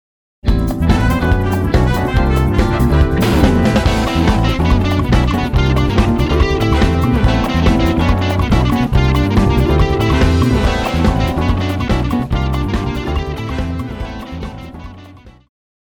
爵士
電吉他
樂團
演奏曲
放克
獨奏與伴奏
有主奏
有節拍器
Guitar 吉他
Trumpet 小號
Tenor Sax 次中音薩克斯
Trombone 長號
Keyboards 鍵盤
Bass 貝斯
Drums 鼓
Percussion 打擊樂器